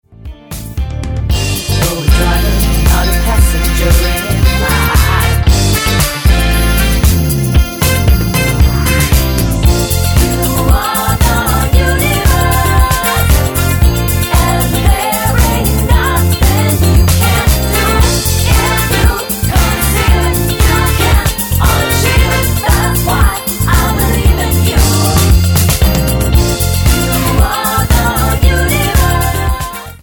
--> MP3 Demo abspielen...
Tonart:F mit Chor